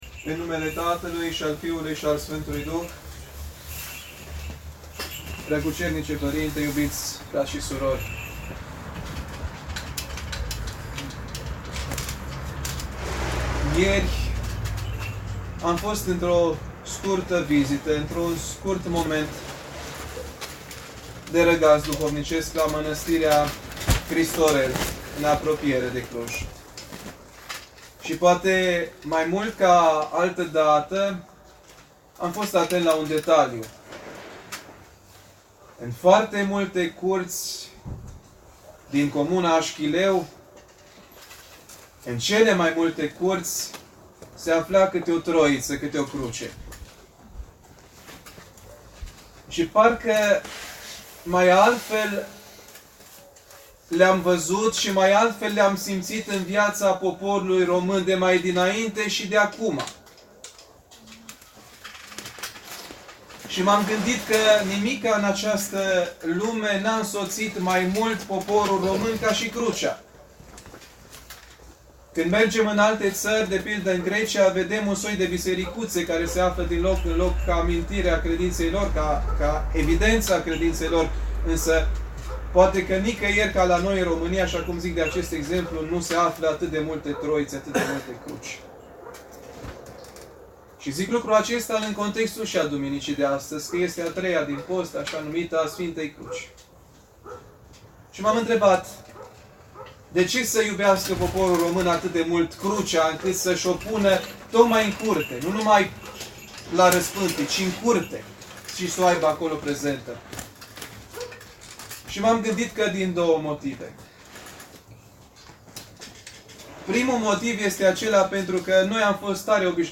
Sfânta Liturghie a fost oficiată în curtea bisericii, într-un cort special amenajat, ca urmare a măsurilor de prevenție şi limitare a răspândirii coronavirusului.
Predica-PS-Benedict-22-martie.mp3